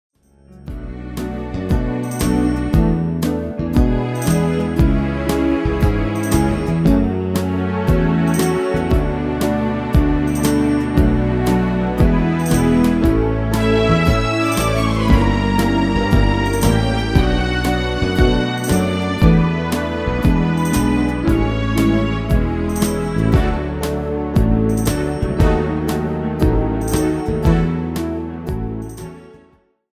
Demo/Koop midifile
Genre: Pop & Rock Internationaal
Demo's zijn eigen opnames van onze digitale arrangementen.